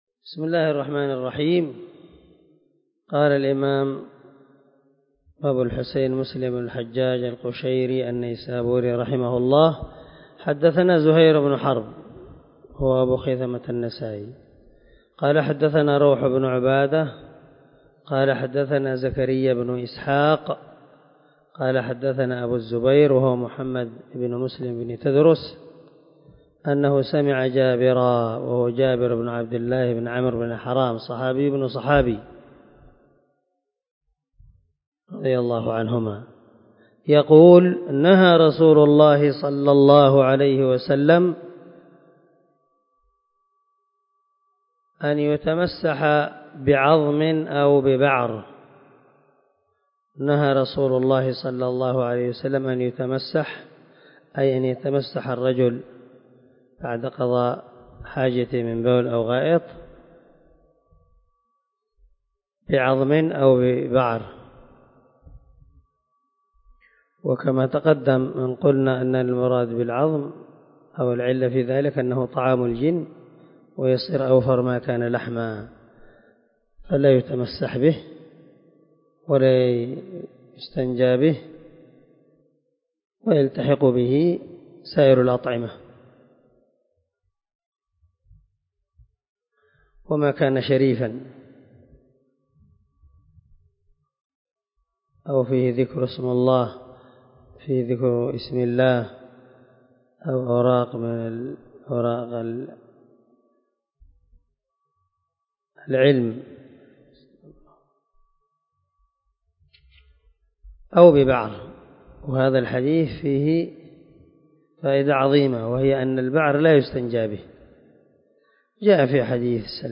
198الدرس 26 من شرح كتاب الطهارة حديث رقم ( 263 – 264 ) من صحيح مسلم